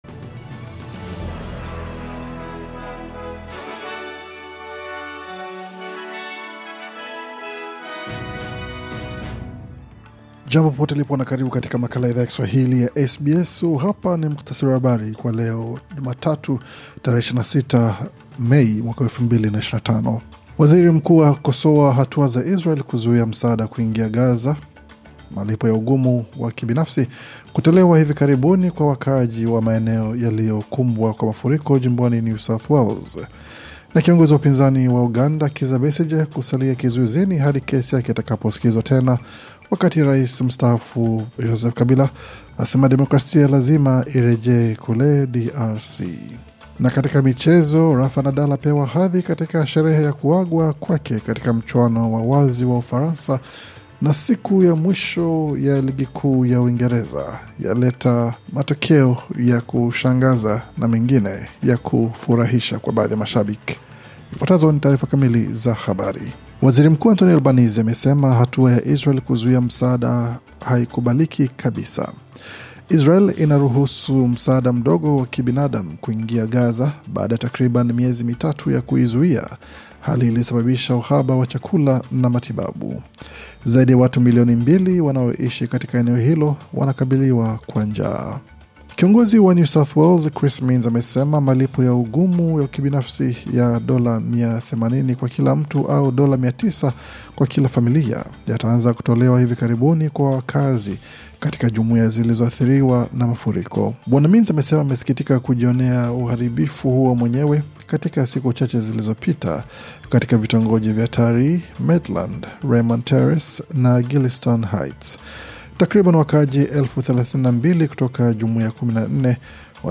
Taarifa ya Habari 26 Mei 2025